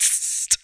Index of /90_sSampleCDs/Best Service - Hallelujah/Partition F/MOUTH PERC
VOCDRUM 21-L.wav